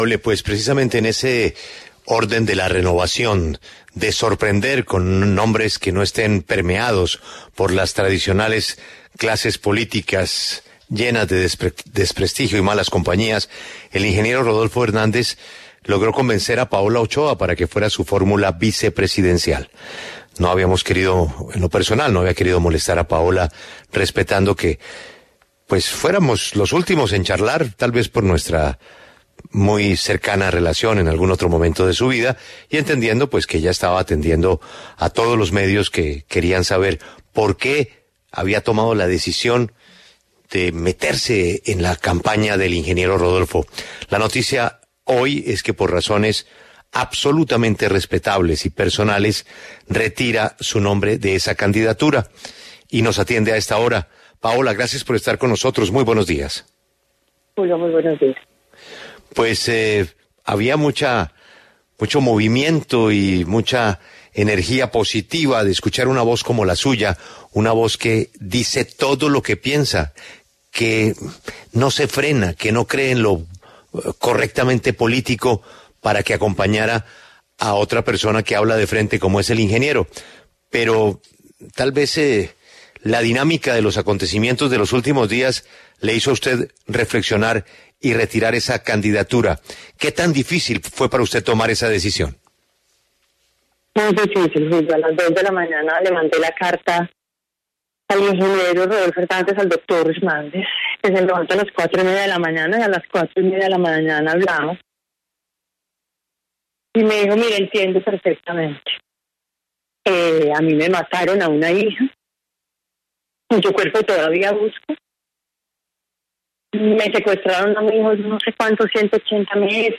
En diálogo con La W, la periodista Paola Ochoa explicó las razones por las que renunció a ser la fórmula vicepresidencial del ingeniero Rodolfo Hernández.